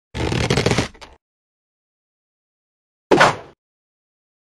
Angry Birds Slingshot Sound Effect